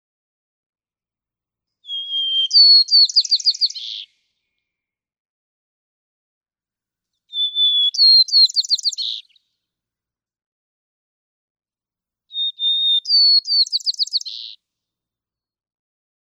White-crowned sparrow
♫240—one song from each of three individuals
240_White-crowned_Sparrow.mp3